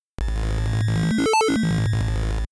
If we change the Phase type to Resync, we’ve just given ourselves another way to add new overtones similar to changing the Cutoff of the wave. Resync, instead of compressing the waveform once and adding 0-value samples at the end, repeats the waveform until all 32 samples are filled.
resync-phase.mp3